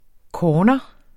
Udtale [ ˈkɒːnʌ ]